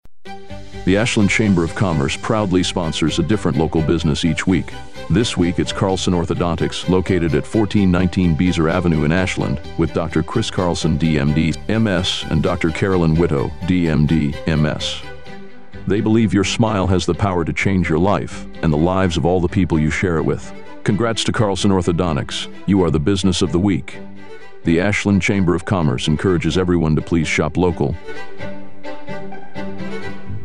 Each week the Ashland Area Chamber of Commerce highlights a business on Heartland Communications radio station WATW 1400AM and Bay Country 101.3FM. The Chamber draws a name at random from our membership and the radio station writes a 30-second ad exclusively for that business.